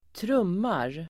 Uttal: [²tr'um:ar]